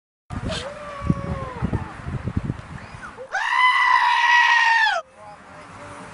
cabra-gritando_1.mp3